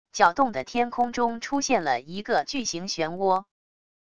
搅动的天空中出现了一个巨型漩涡wav音频